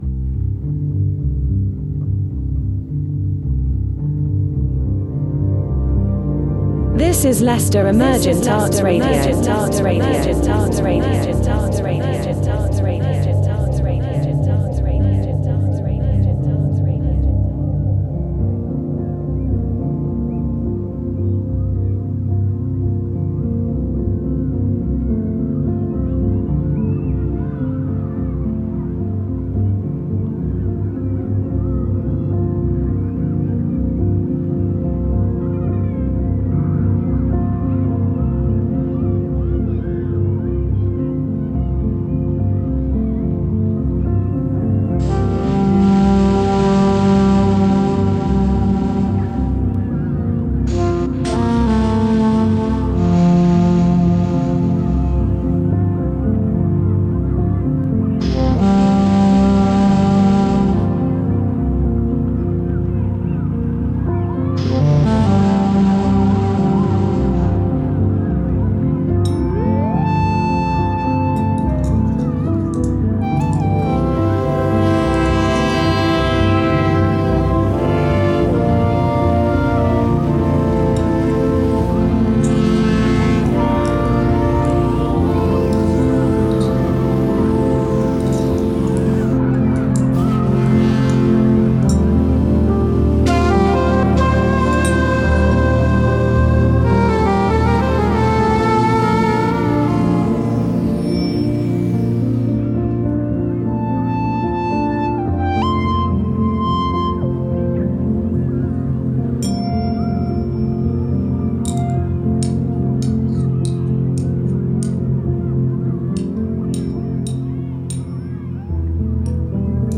In episode 21 of the Distraction Therapy Podcast, listeners are invited to explore profound themes of human nature through a metamodern mix of spoken word and evocative music. This episode features reflections from Carl Jung, Marie Louise von Frans, and James Hillman, seamlessly integrated with a diverse selection of ambient and contemplative music.